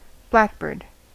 Ääntäminen
US : IPA : [ˈblæk.ˌbɝd] UK : IPA : /ˈblakbəːd/